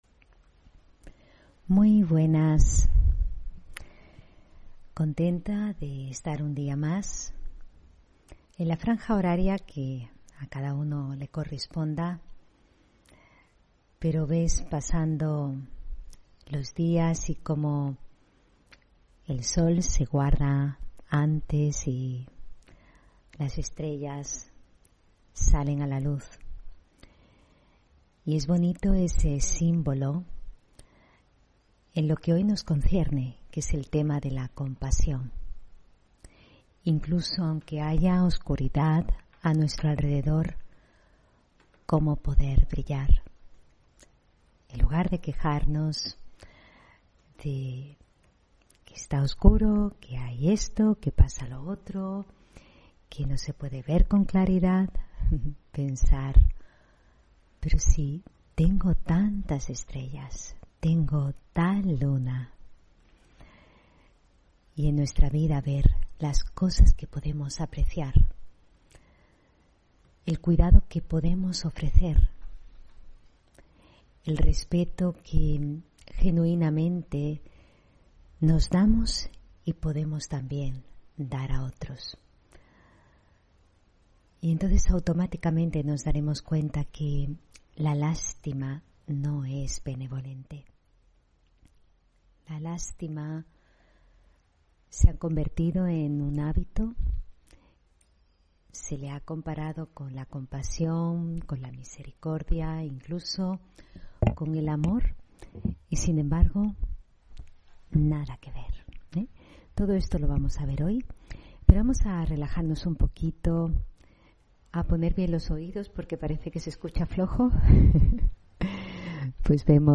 Meditación y conferencia: Compasión o lástima (17 Noviembre 2021)